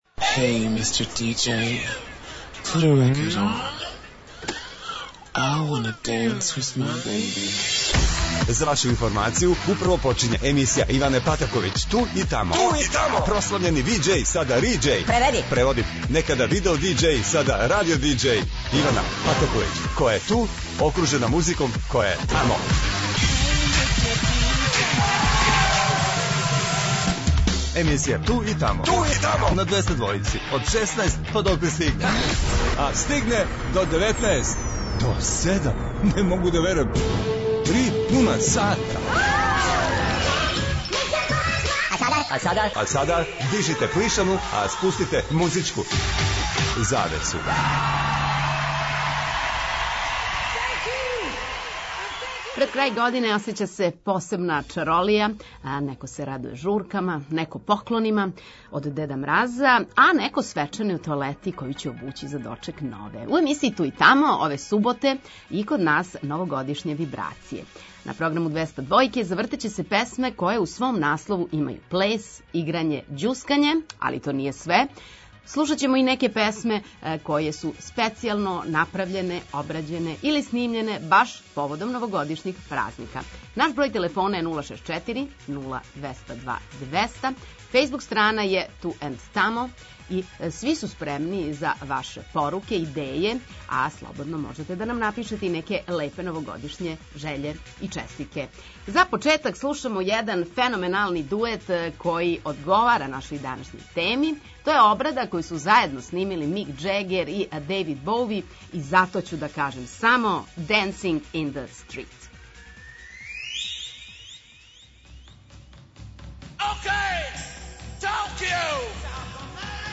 И у емисији „Ту и тамо” ове суботе - новогодишње вибрације. На програму Двестадвојке завртеће се песме које у свом наслову имају плес, играње, ђускање...